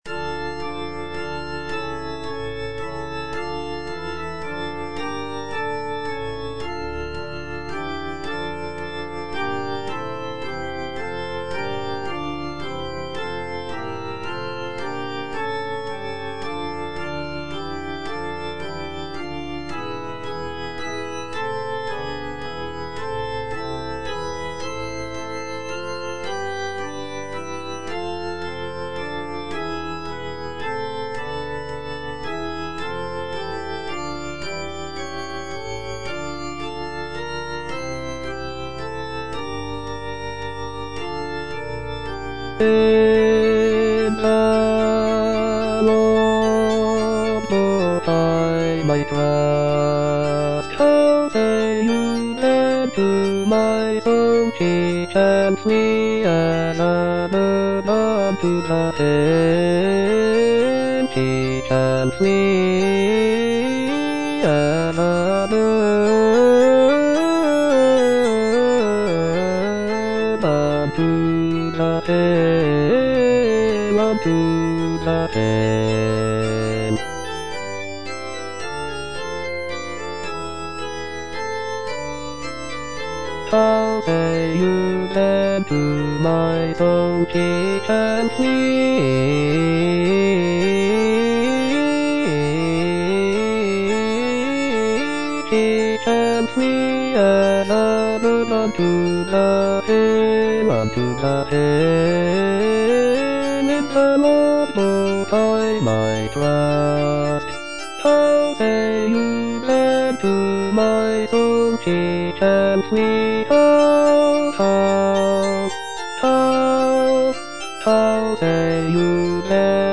Choralplayer playing In the Lord put I my trust - Chandos anthem no. 2 (SATB version) HWV247 by G.F. Händel (arr. D. Cranmer) based on the edition Novello Publishing Ltd. NOV060137
G.F. HÄNDEL - IN THE LORD PUT I MY TRUST HWV247 (SATB VERSION) In the Lord put I my trust - Bass (Voice with metronome) Ads stop: auto-stop
It features a four-part chorus (soprano, alto, tenor, and bass) and is set to a biblical text from Psalm 11, expressing trust and reliance on the Lord.